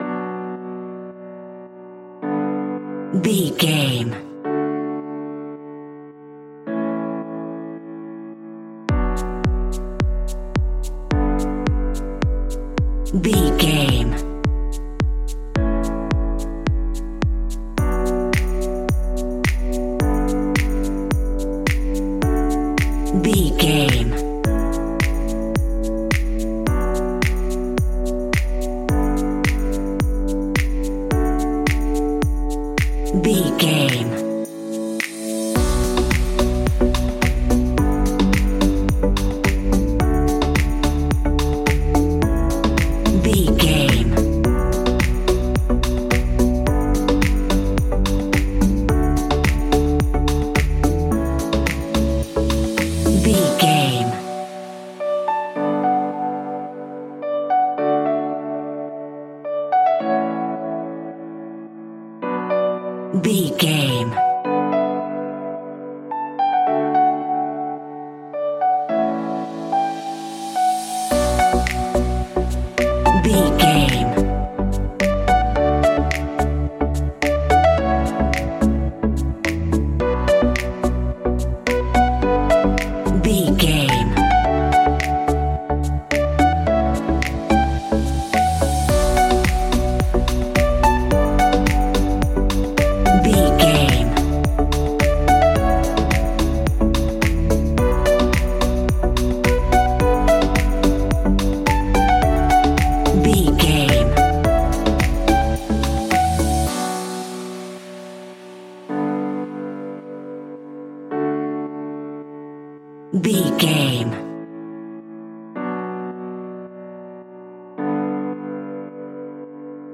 Aeolian/Minor
uplifting
energetic
bouncy
synthesiser
drum machine
house
electro house
synth leads
synth bass